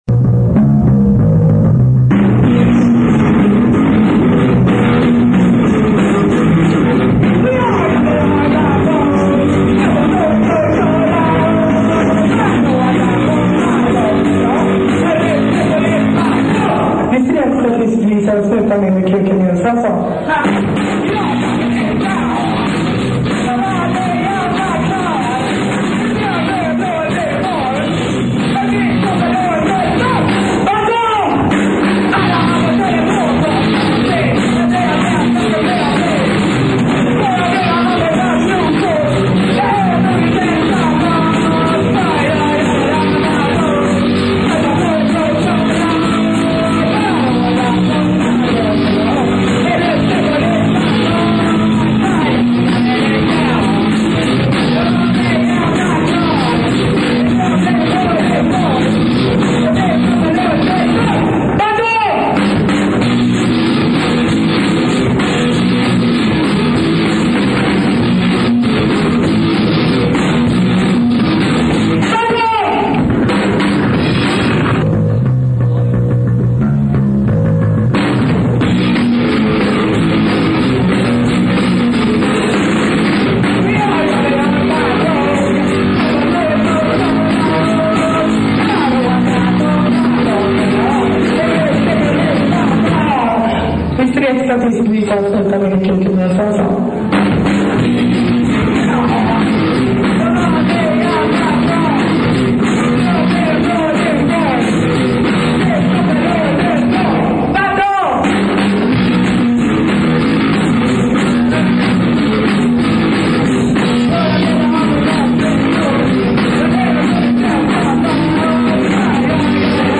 Sax – Gitarr
Trummor